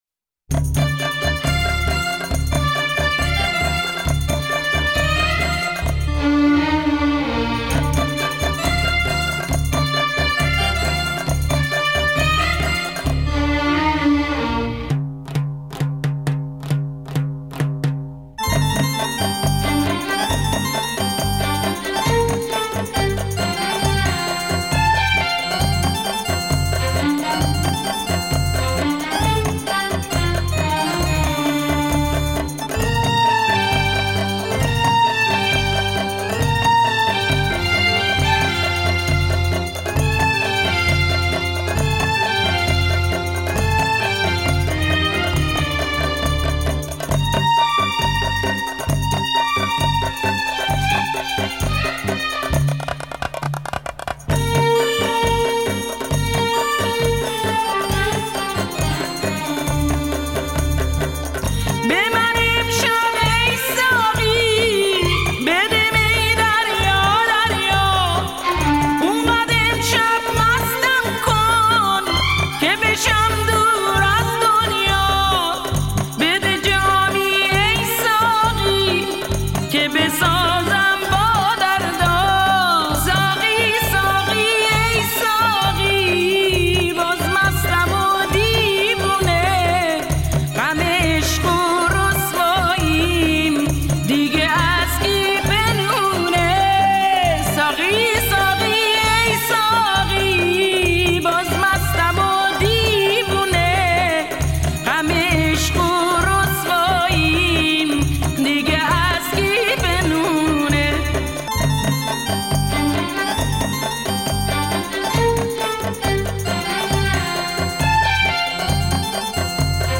تنظیم راک